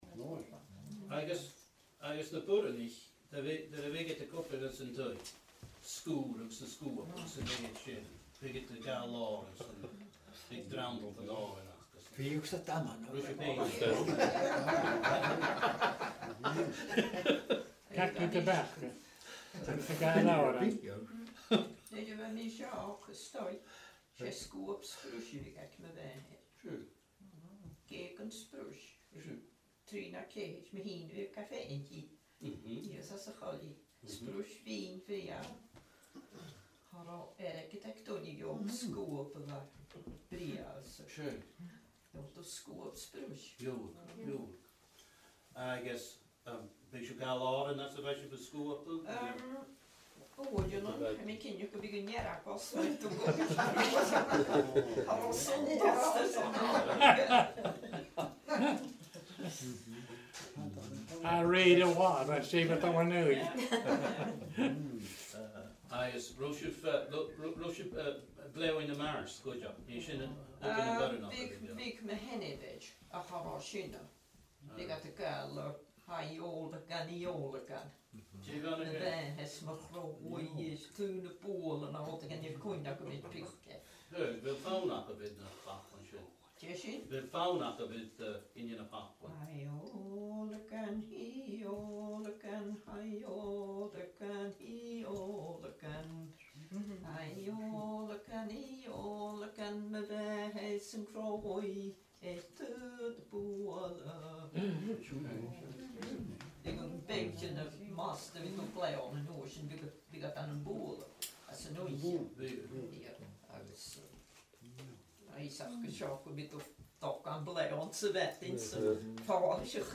An Clachan Gàidhealach, Sanndraigh
Agallamh